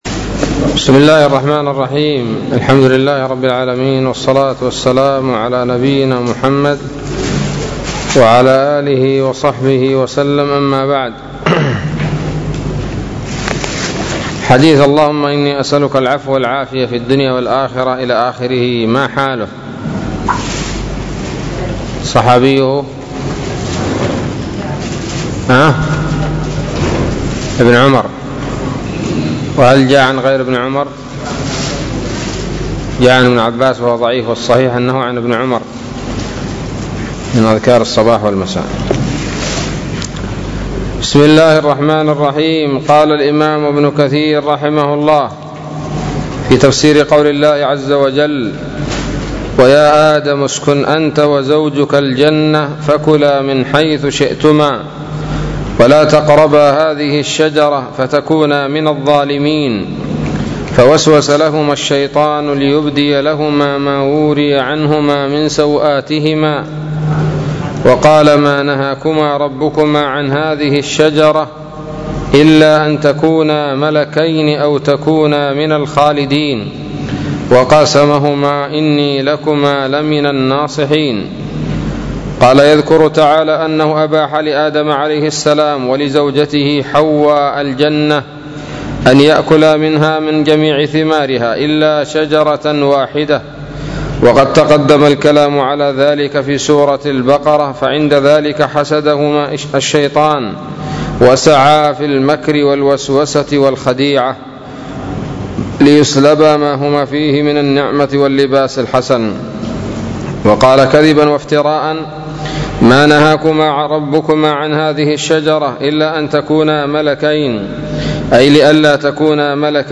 الدرس السابع من سورة الأعراف من تفسير ابن كثير رحمه الله تعالى